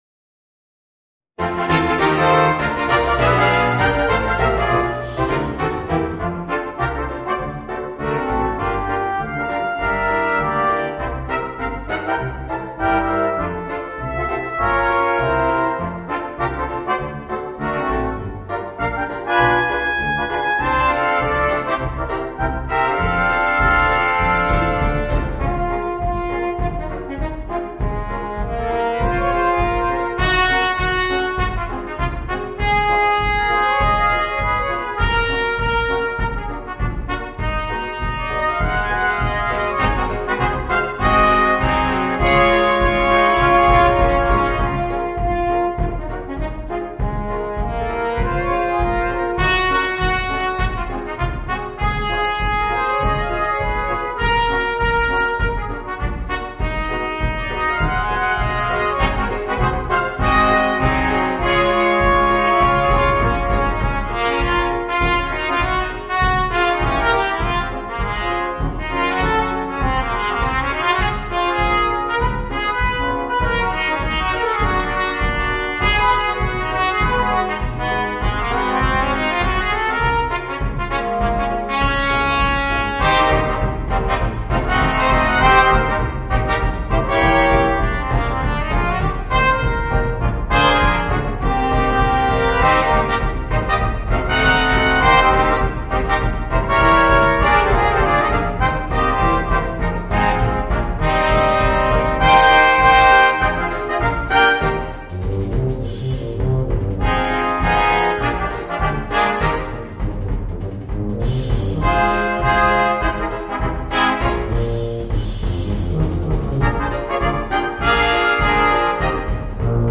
Voicing: 3 Trumpets and 2 Trombones